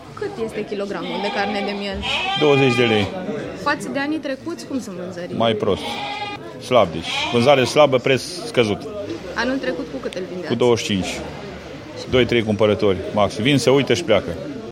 Un crescător de ovine a declarat pentru Radio Reșița ,,Vânzare slabă, preț scăzut, 2-3 cumpărători, vin, se uită și pleacă’’.